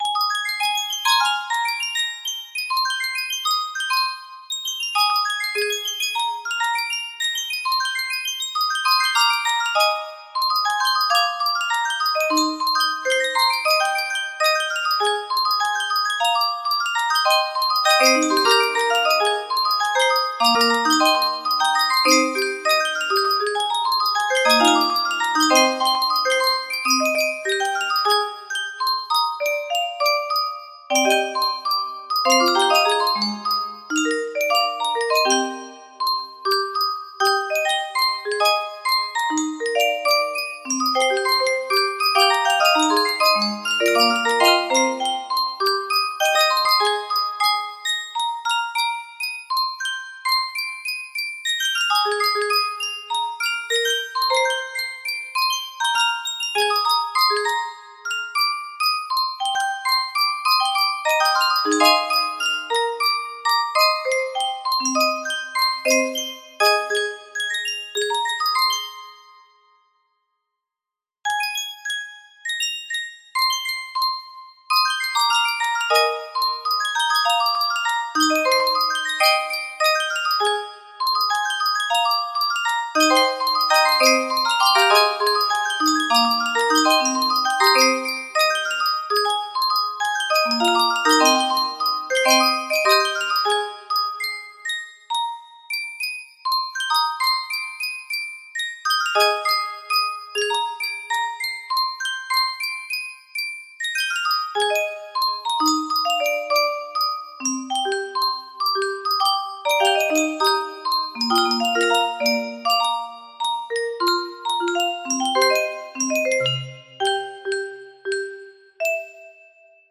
Threads Of Gold 1 music box melody
Full range 60